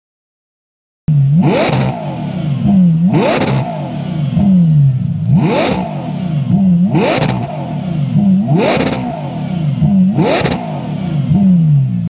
Capristo präsentiert Ihnen einen hochwertigen Endschalldämpfer mit Abgasklappen für den Ferrari Purosangue.
Purosangue-X-Pipe.mp3